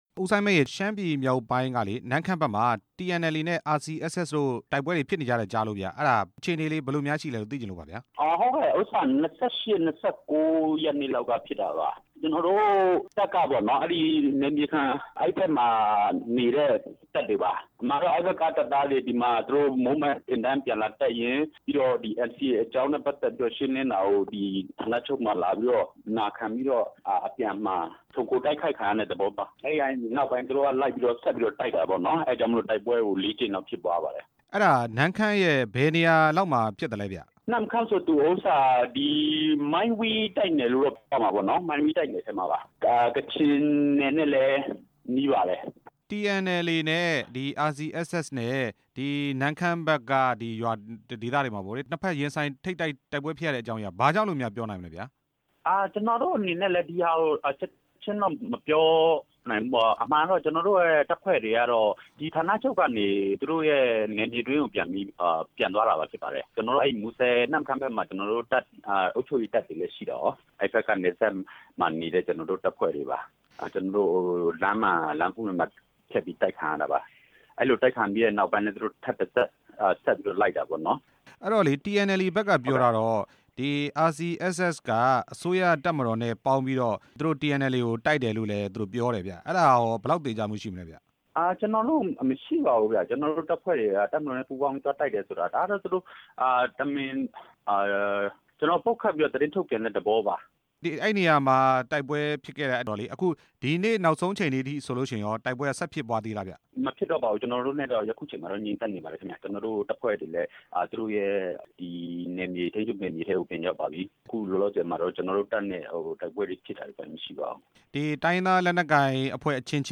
TNLA နဲ့ RCSS/SSA တို့ တိုက်ပွဲဖြစ်ပွားရတဲ့အကြောင်းရင်း မေးမြန်းချက်